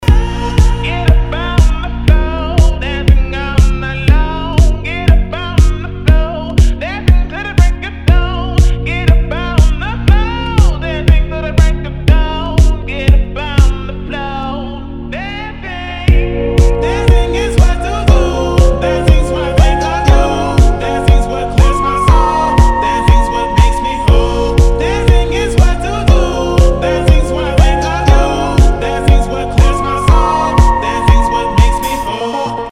• Качество: 320, Stereo
ритмичные
deep house
заводные